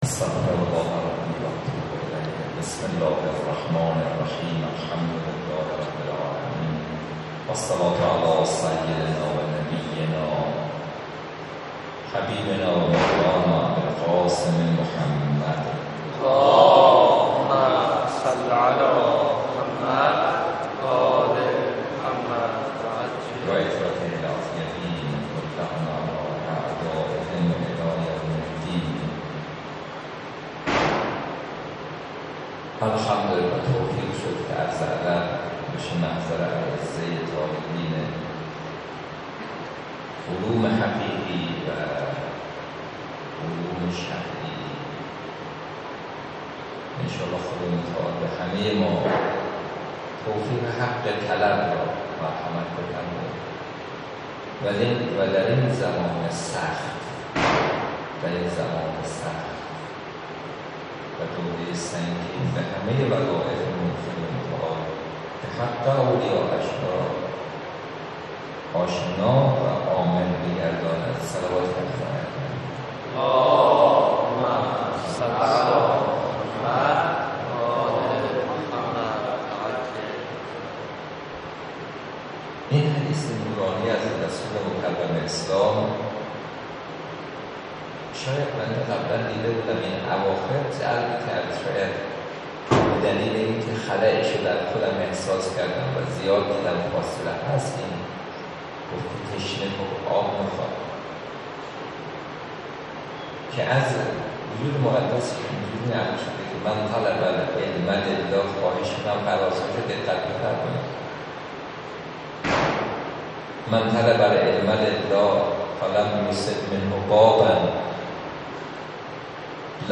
درس الاخلاق